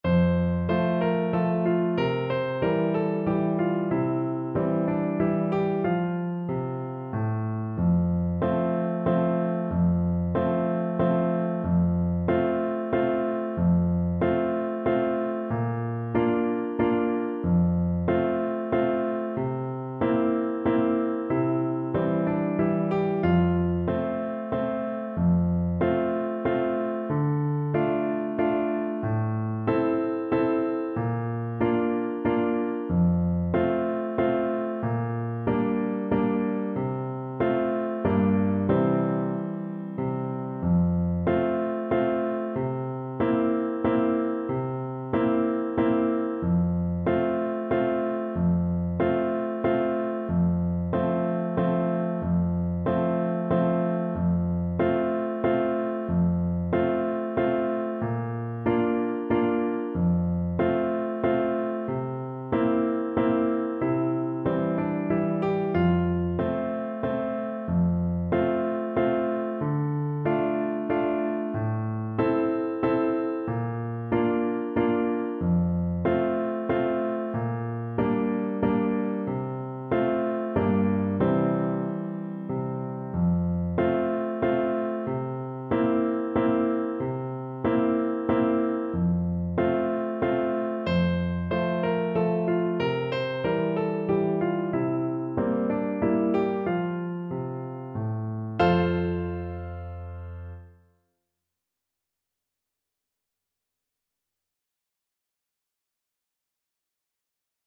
Play (or use space bar on your keyboard) Pause Music Playalong - Piano Accompaniment Playalong Band Accompaniment not yet available transpose reset tempo print settings full screen
Voice
F major (Sounding Pitch) (View more F major Music for Voice )
3/4 (View more 3/4 Music)
One in a bar .=c.45
Traditional (View more Traditional Voice Music)